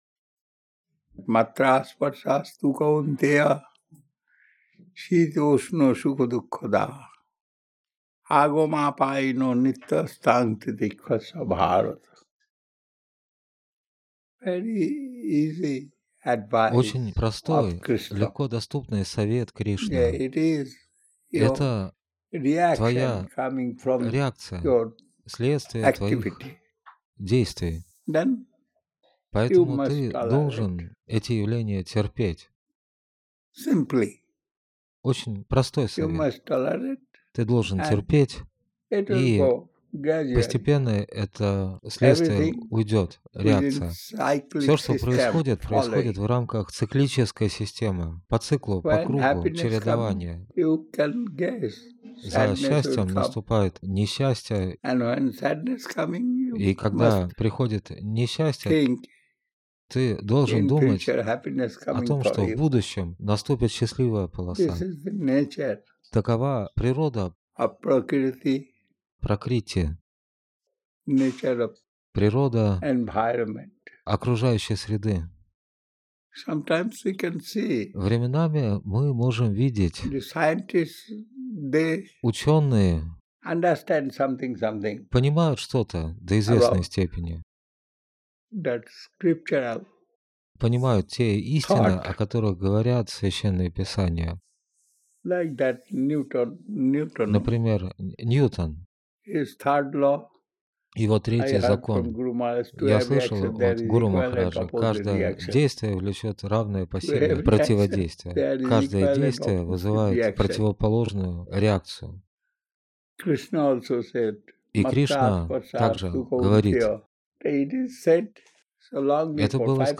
Навадвипа Дхама, Индия